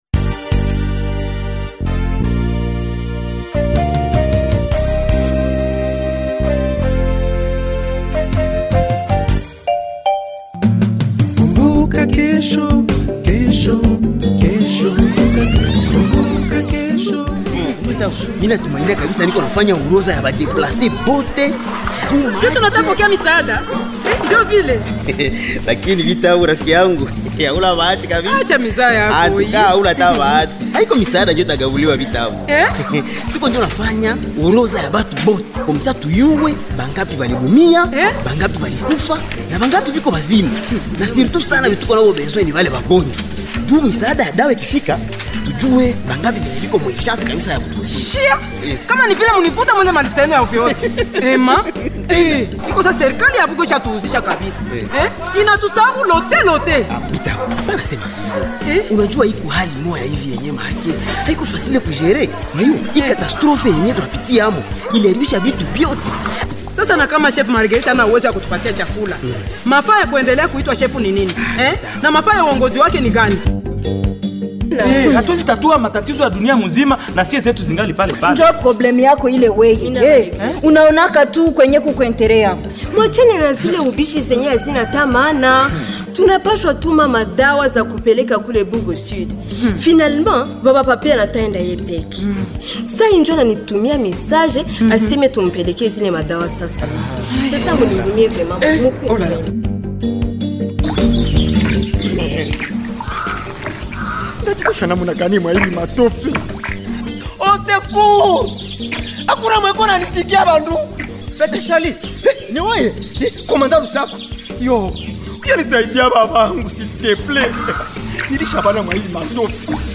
Voici le feuilleton Kumbuka Kesho du 29 décembre 2025 au 04 janvier 2026
Le feuilleton Kumbuka Kesho est à suivre sur toutes les radios partenaires de la Benevolencija ou directement ici en cliquant sur ce son :